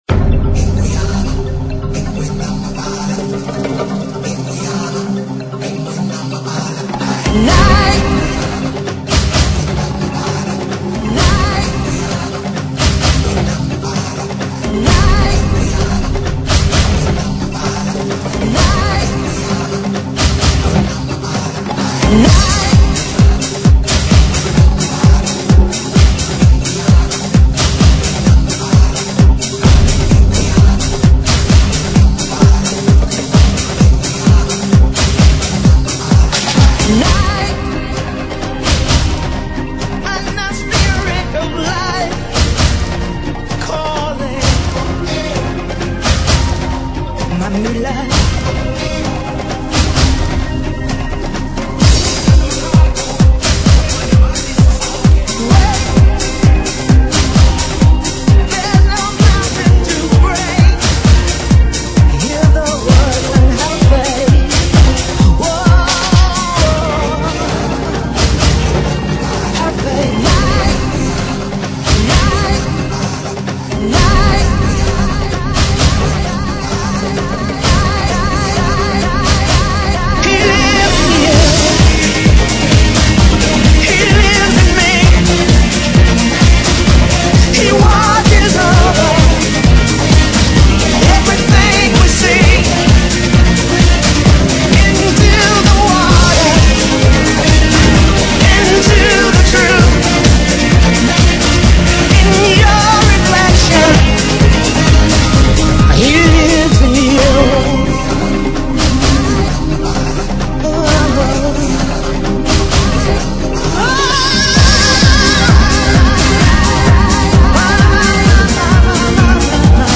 Dance Para Ouvir: Clik na Musica.